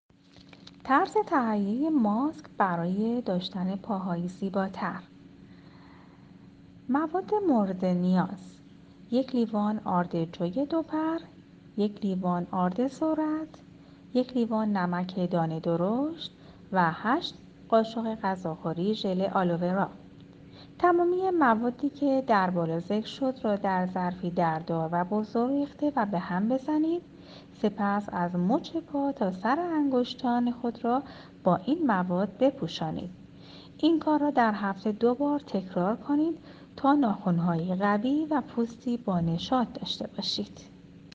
مشاوره صوتی